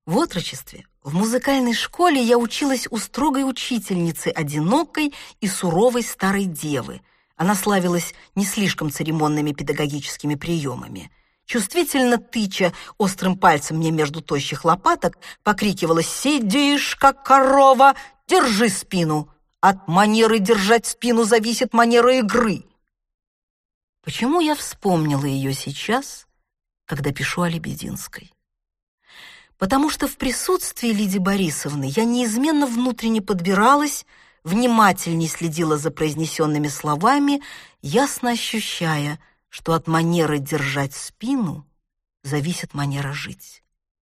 Аудиокнига В России надо жить долго | Библиотека аудиокниг
Aудиокнига В России надо жить долго Автор Дина Рубина Читает аудиокнигу Дина Рубина.